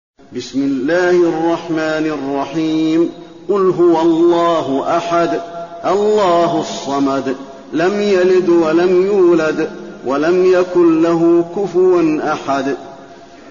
المكان: المسجد النبوي الإخلاص